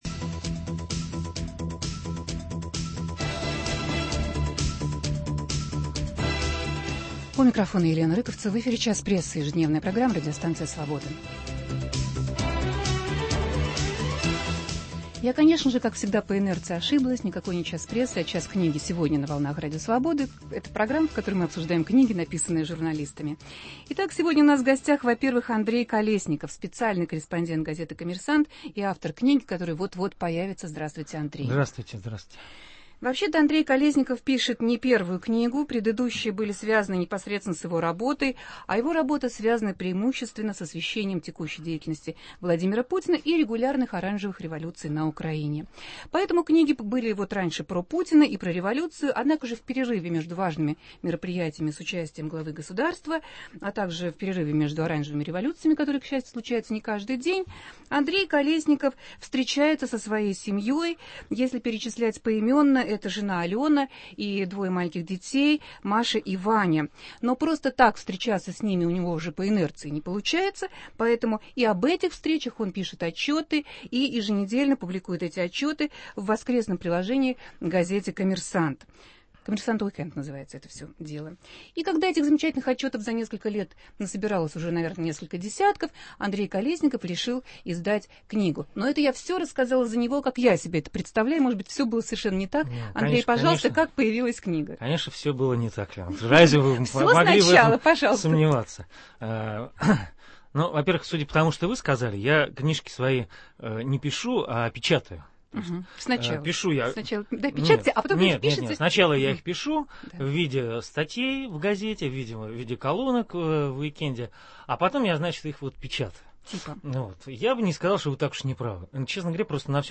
Обсуждаем книгу обозревателя газеты "Коммерстант" Андрея Колесникова "Отцеводство". В студии автор и отцы-оппоненты